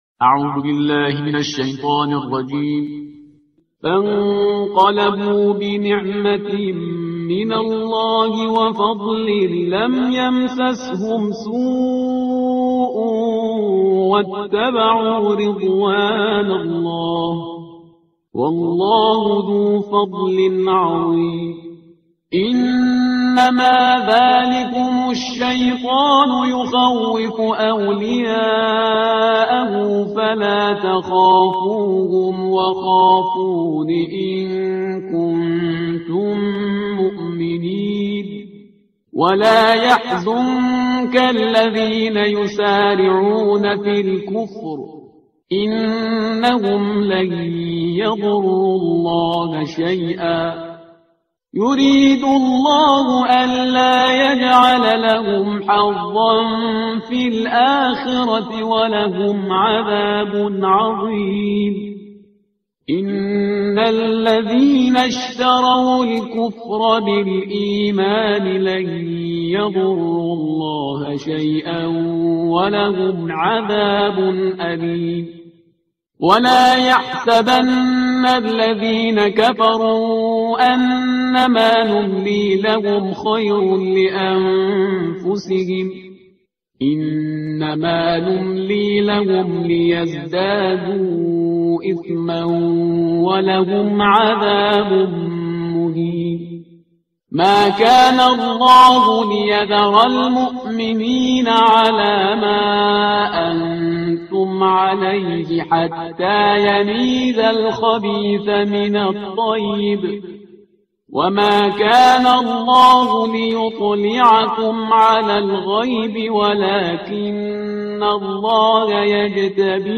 ترتیل صفحه 73 قرآن – جزء چهارم